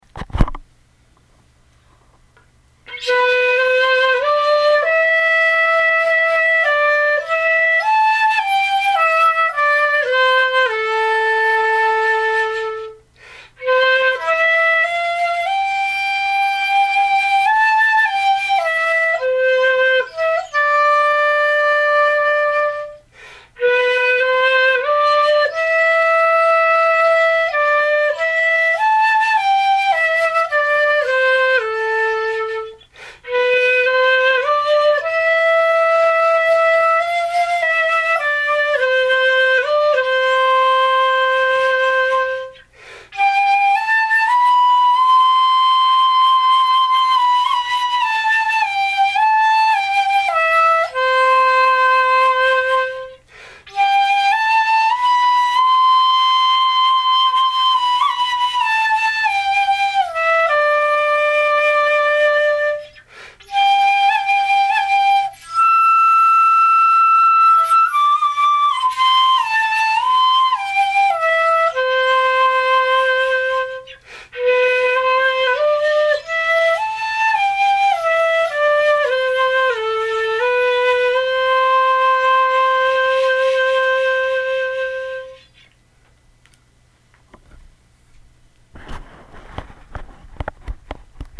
[曲danny boy 6寸管]。
次に、息の支えを付けたつもりが、、、
うーーん、吠えているような、、、